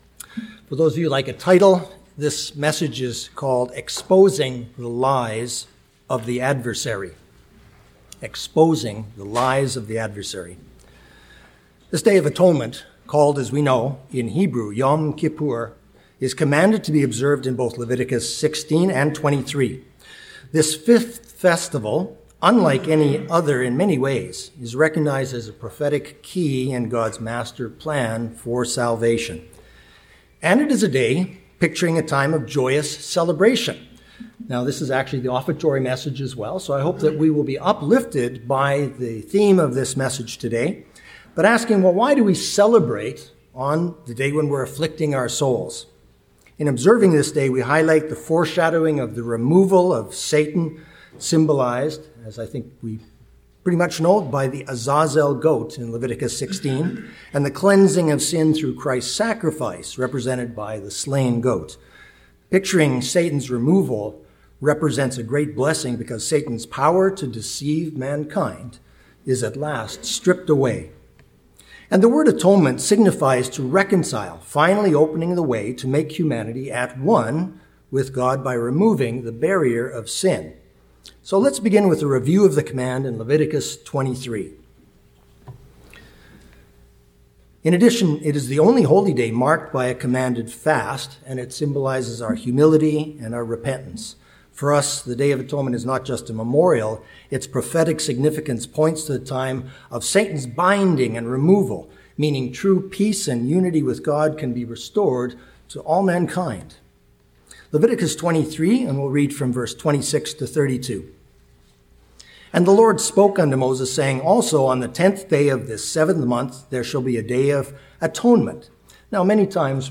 Sermons
Given in Chicago, IL Beloit, WI Northwest Indiana